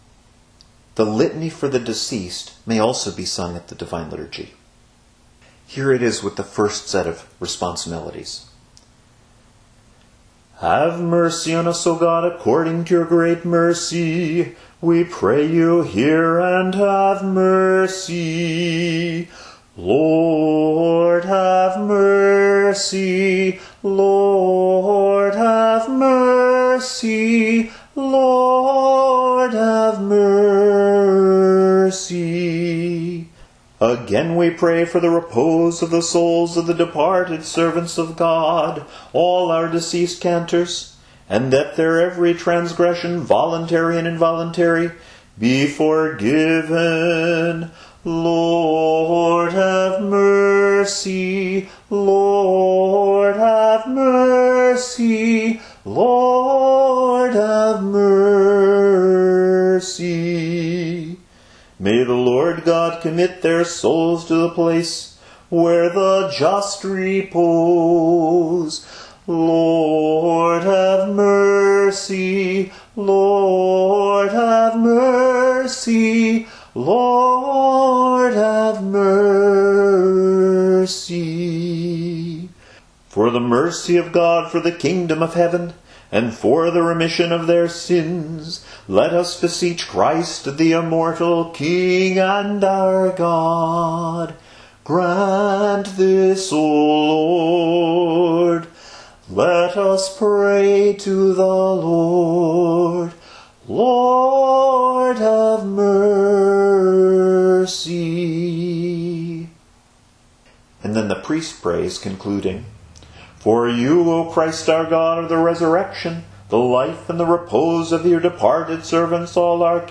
Note the addition of the leading tone (si, a raised so) to emphasize the minor key.
The second set of responses is in a major key, but are otherwise used the same way: